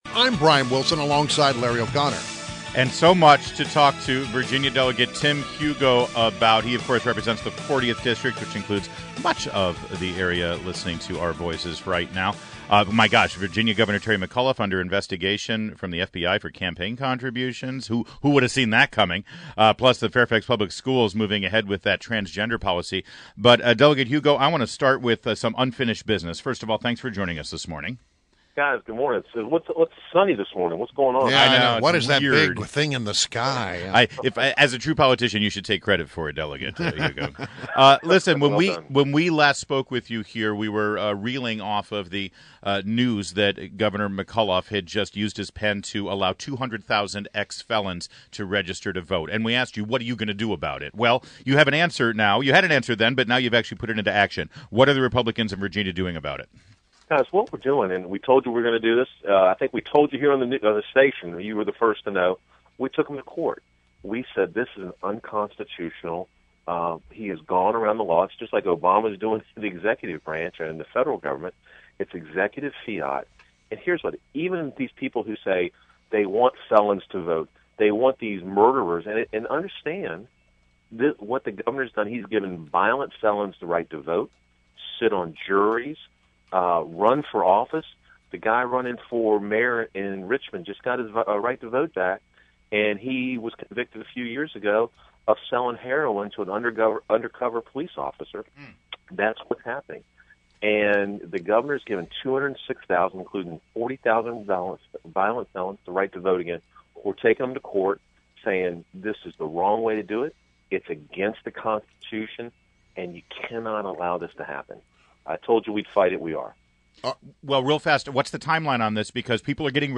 WMAL Interview - VA Del. TIM HUGO 05.24.16
INTERVIEW – VA DEL TIM HUGO — representing the 40th district, which includes the municipalities of Catharpin, Clifton, and Fairfax Station and is the Majority Caucus Chairman for the Virginia House Republican Caucus